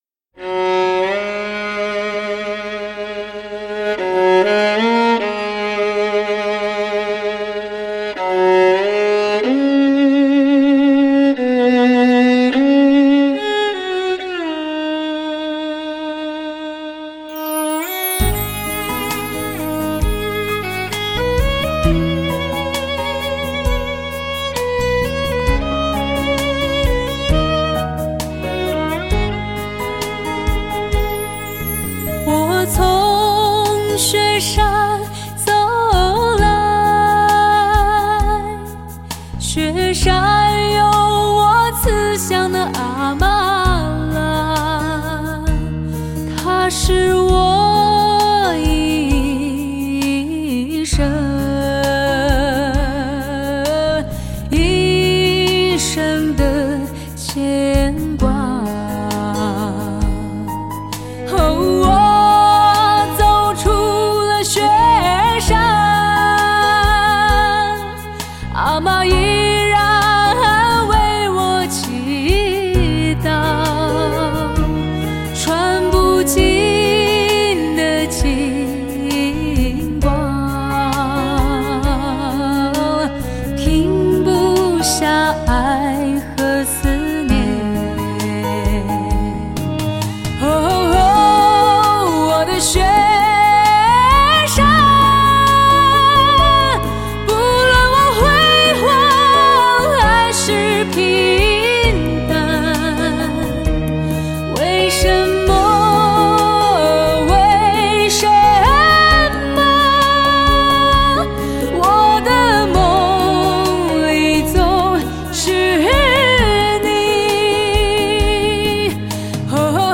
一个醇厚清亮的女伶，一把天籁般的嗓音，用歌声带人们去遨游那片纯净唯美的圣地；